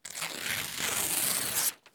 ripping-paper.wav